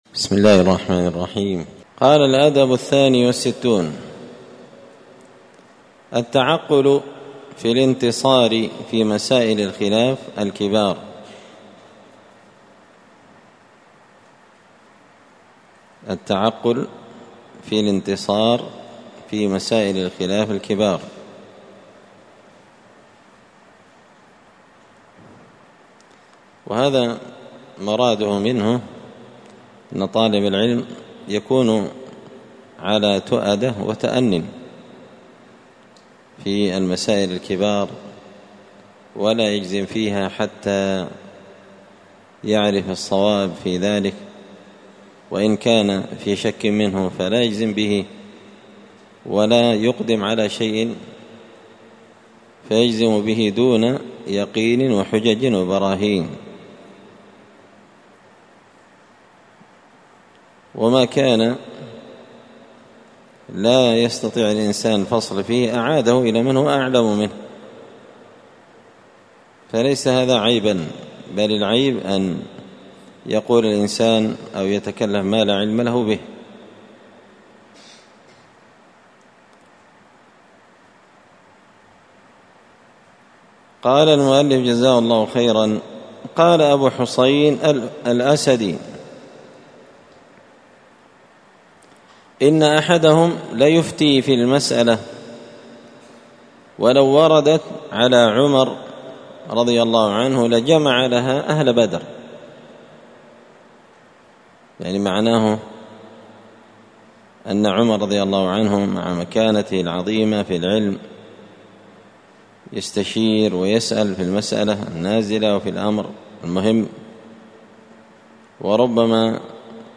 الأثنين 15 ذو الحجة 1444 هــــ | الدروس، النبذ في آداب طالب العلم، دروس الآداب | شارك بتعليقك | 13 المشاهدات
مسجد الفرقان قشن_المهرة_اليمن